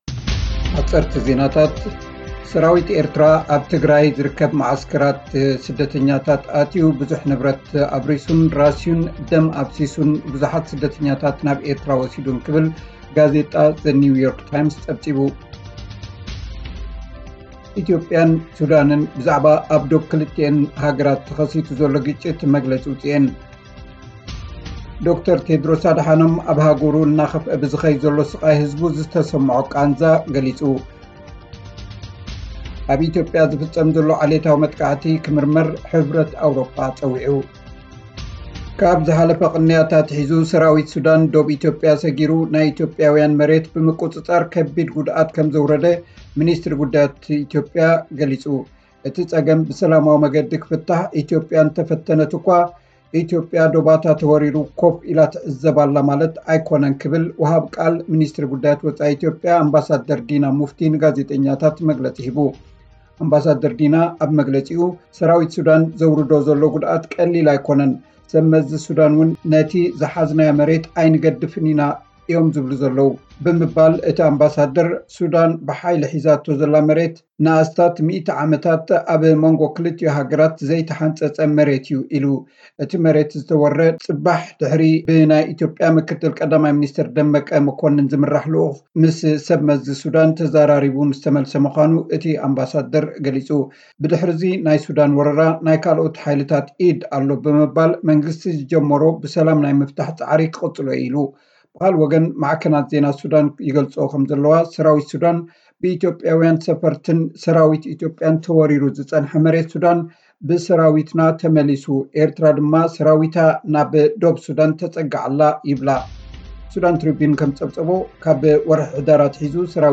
(ጸብጻብ)